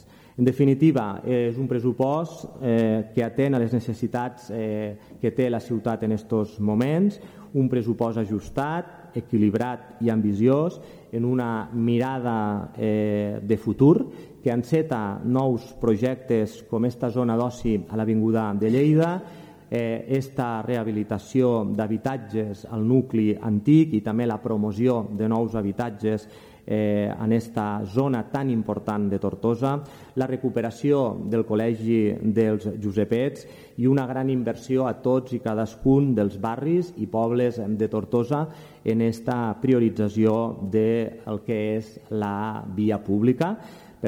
El Govern de Movem-PSC i ERC ha aprovat amb el suport de la CUP el pressupost per al 2025, en un ple extraordinari celebrat aquest divendres.